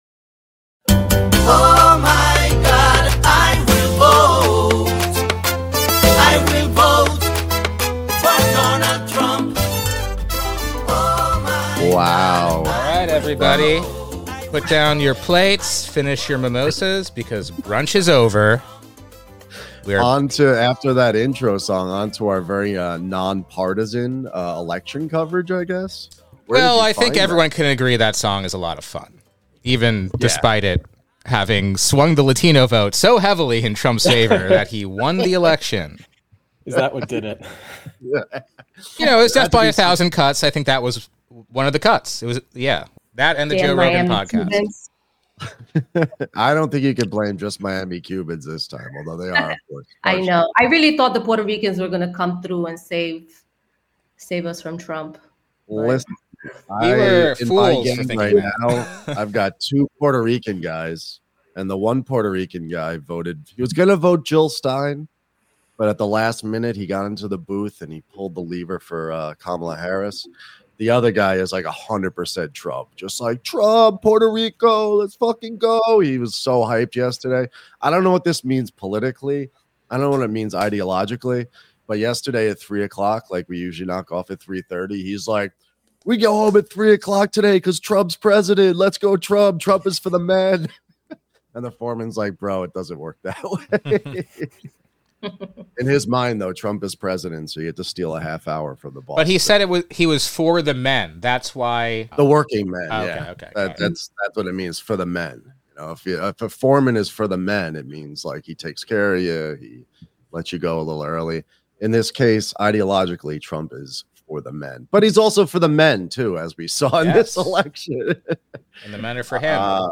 Our roundtable discusses the meaning of Trump's victory--the election's stakes, its international reverberations, what it tells us about the crisis of capitalist accumulation, how economics connect with the the race/class/gender demographic voting shifts, and the historic characters of the Republican and Democratic Parties.